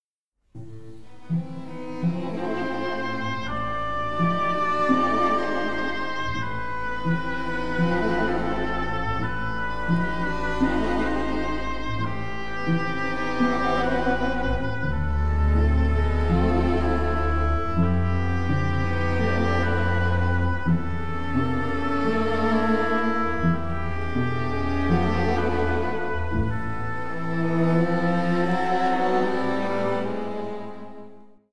strings
piano